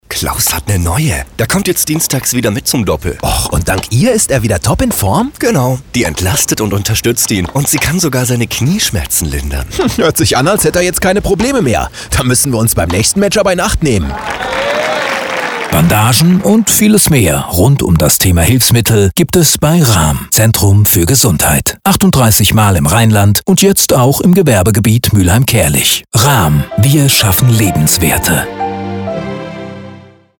Radiospots produziert und 1.000 mal geschaltet.
Verpackt ist die Botschaft in kleine Alltagsdialoge, in unterhaltsamer und eingängiger Form. Vier verschiedene Themen werden in 30 sekündigen Spots aufgegriffen, deren Zusammengehörigkeit und Wiedererkennungswert durch gleiche Machart und Stimmen sowie den Jingle sehr hoch ist. Jeder Spot endet mit einer direkten Ansprache der avisierten Zielgruppe.